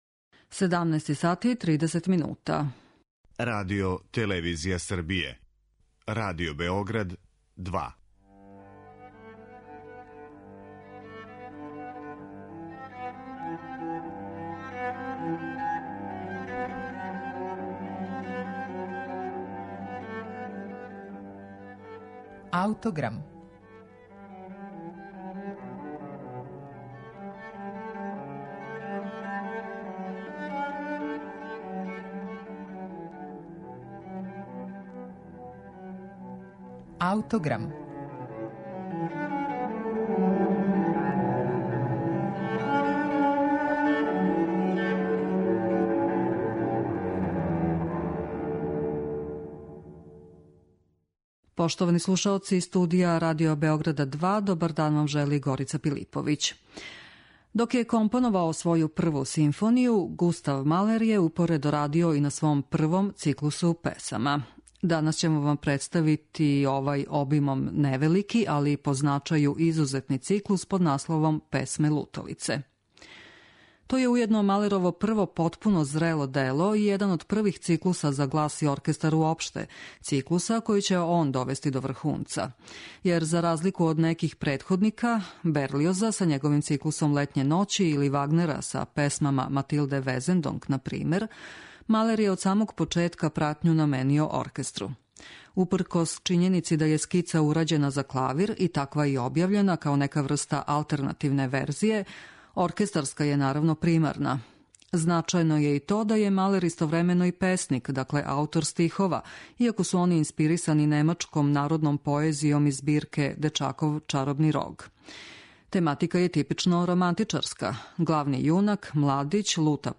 То је уједно Малерово прво потпуно зрело дело и један од првих циклуса за глас и оркестар уопште, циклуса који ће он довести до врхунца.